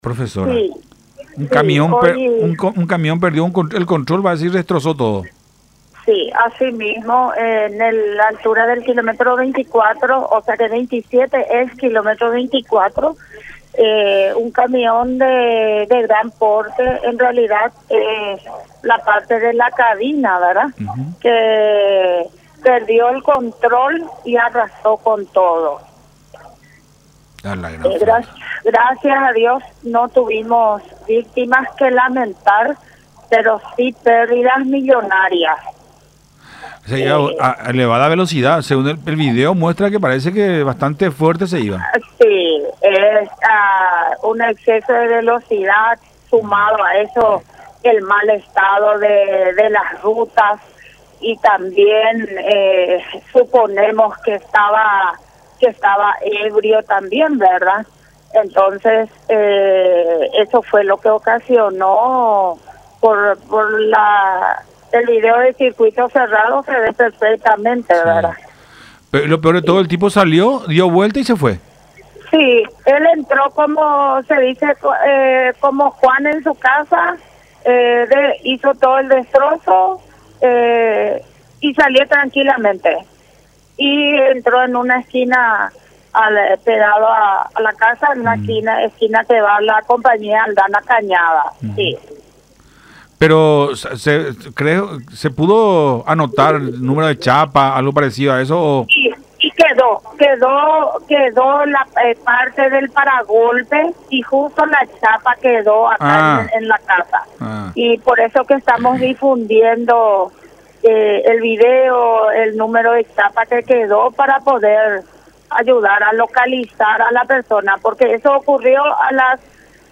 en diálogo con Nuestra Mañana a través de Unión TV y radio La Unión.